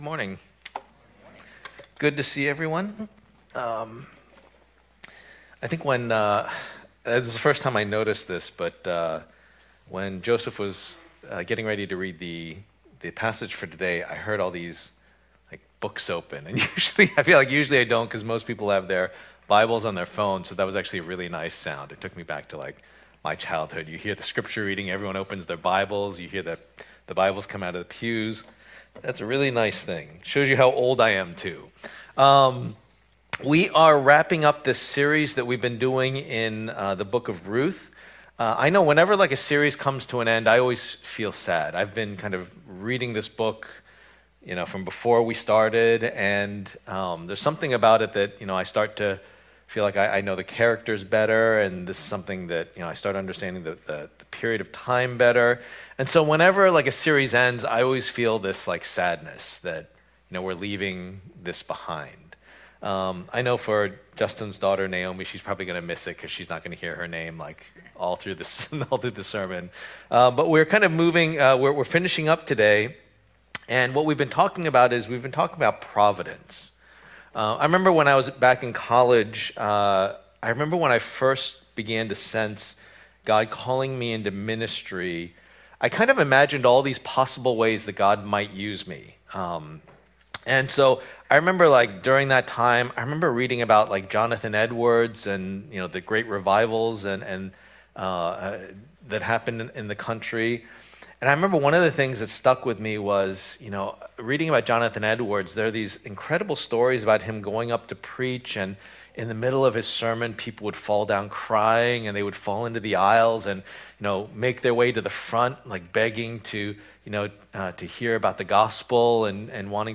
Passage: Ruth 4:1-22 Service Type: Lord's Day %todo_render% « An Ancient Love Story Is God in Control?